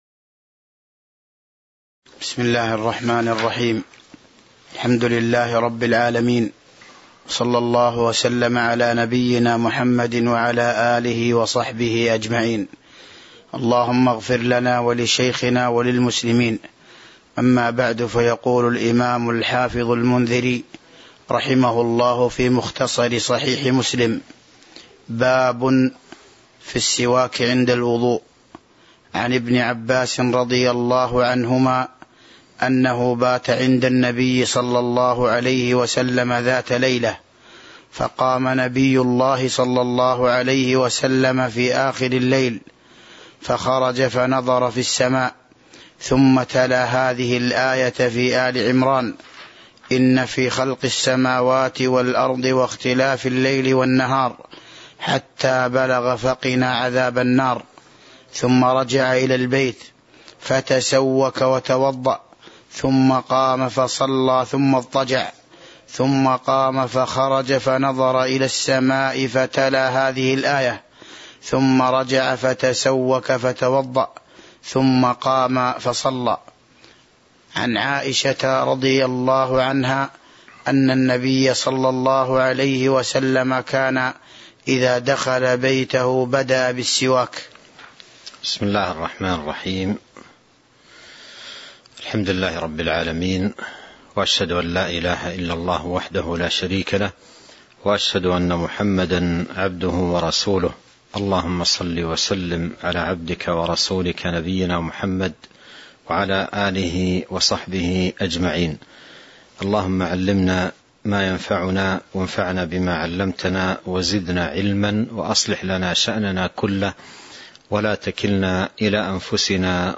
تاريخ النشر ٢٦ ربيع الأول ١٤٤٢ هـ المكان: المسجد النبوي الشيخ